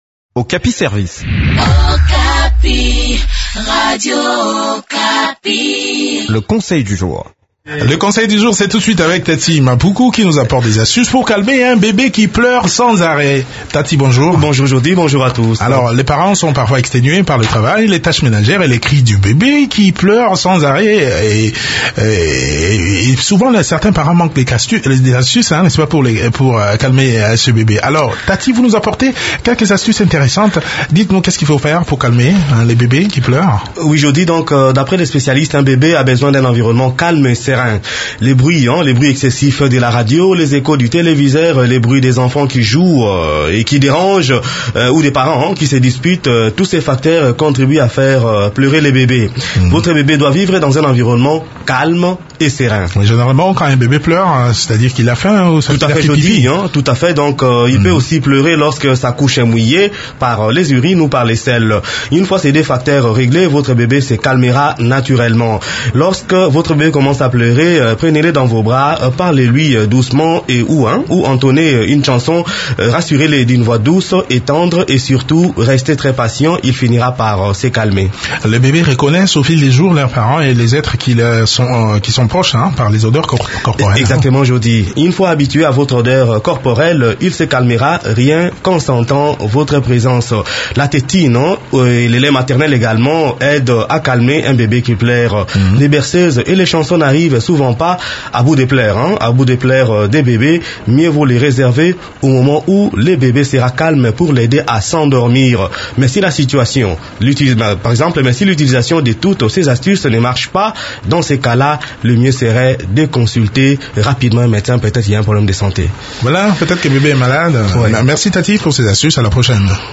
Découvrez des astuces qui peuvent vous aider à calmer un bébé qui pleure sans arrêt dans cette chronique